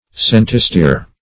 Search Result for " centistere" : The Collaborative International Dictionary of English v.0.48: Centistere \Cen"ti*stere\, n. [F. centist[`e]re; centi- (l. centum) + st[`e]re.] The hundredth part of a stere, equal to 0.353 cubic feet.